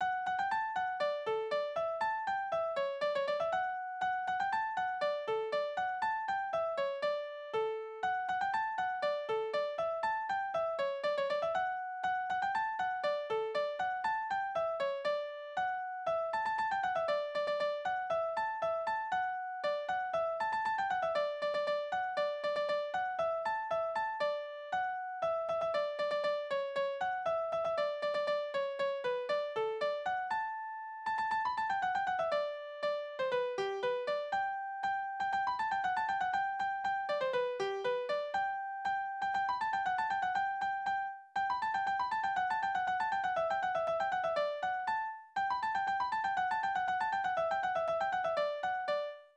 « 10898-1 » Kastilianer Tanzverse Tonart: D-Dur Taktart: 2/4 Tonumfang: große Dezime Externe Links